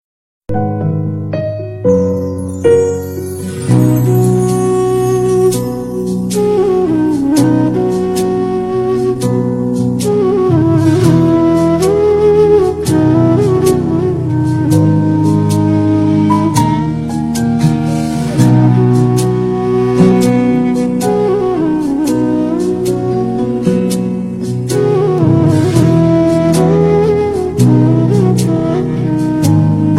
flute basuri Category